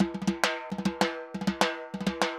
Timba_Candombe 100_5.wav